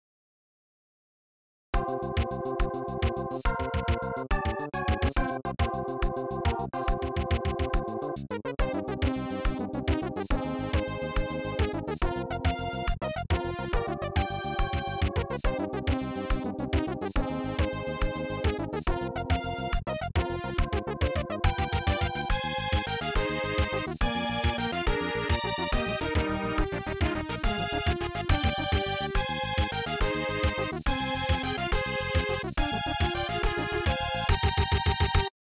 Sounds like the opening of a sentai show: